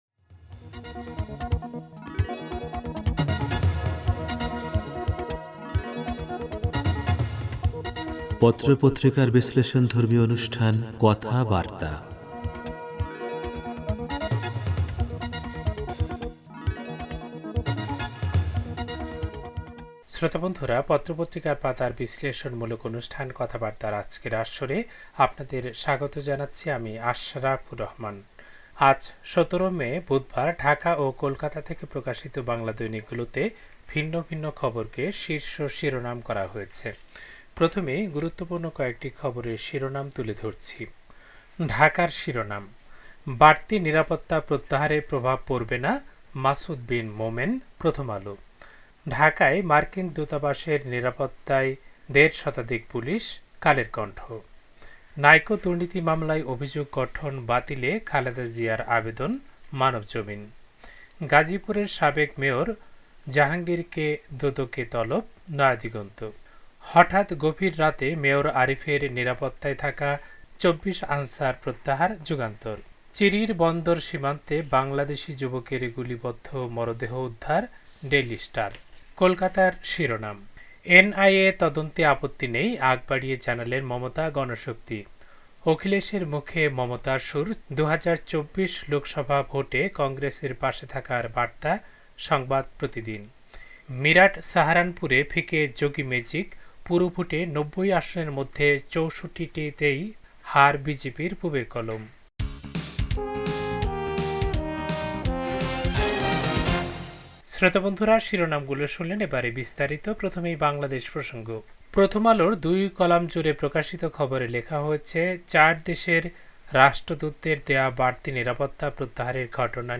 পত্রপত্রিকার পাতার অনুষ্ঠান কথাবার্তা